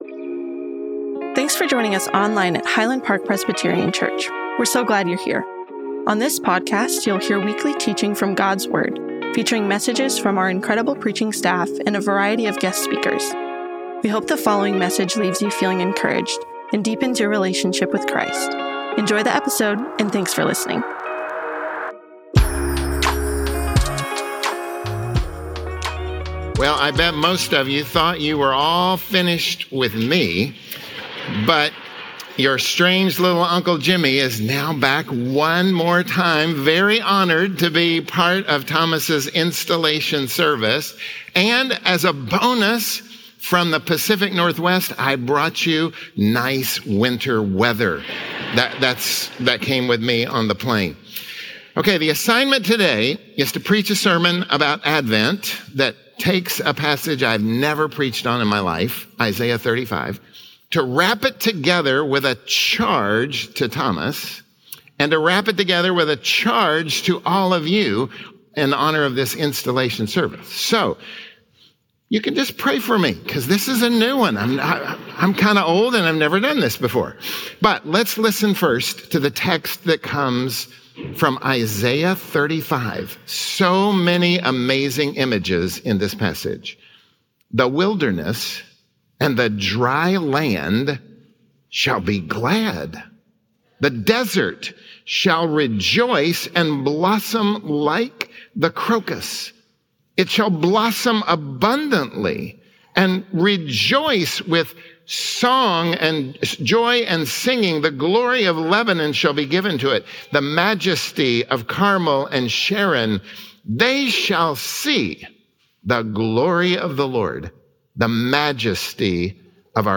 Listen to messages from Highland Park Presbyterian Church.